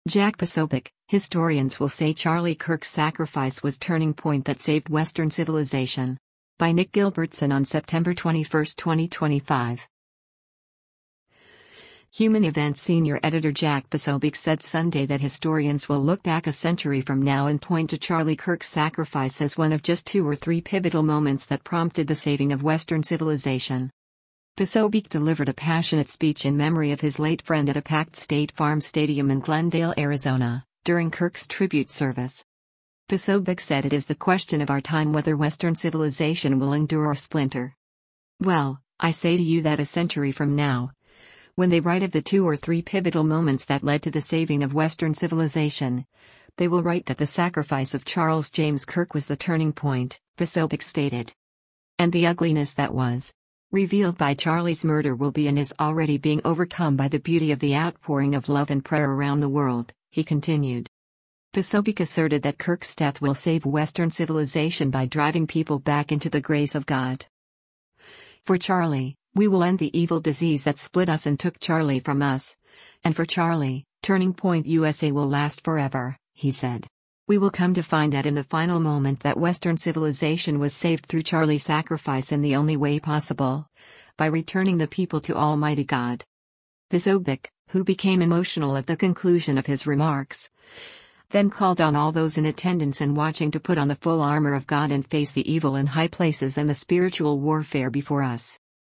Posobiec delivered a passionate speech in memory of his late friend at a packed State Farm Stadium in Glendale, Arizona, during Kirk’s tribute service.
Posobiec, who became emotional at the conclusion of his remarks, then called on all those in attendance and watching “to put on the full armor of God and face the evil in high places and the spiritual warfare before us.”